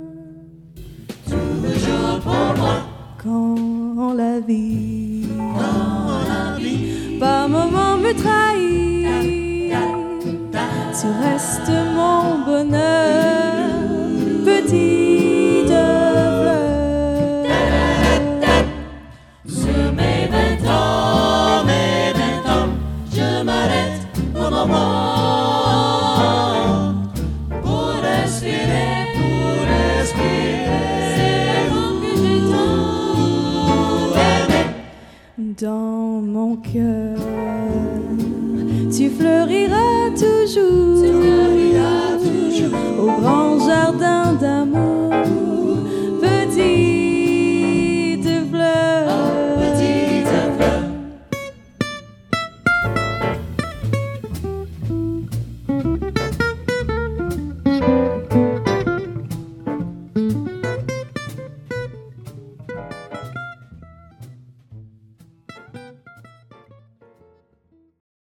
SATB – piano, basse & batterie